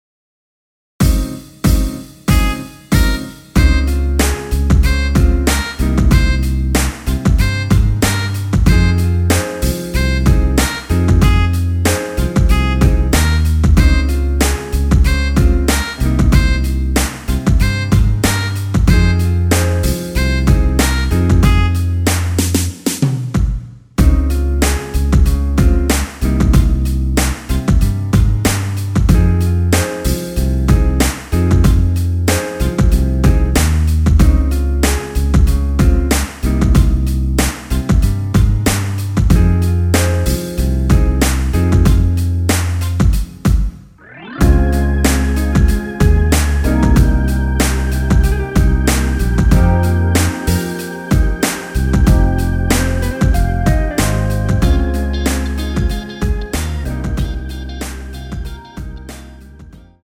엔딩이 페이드 아웃이라 가사 까지 하고 끝나게 만들어 놓았습니다.
Ab
앞부분30초, 뒷부분30초씩 편집해서 올려 드리고 있습니다.
중간에 음이 끈어지고 다시 나오는 이유는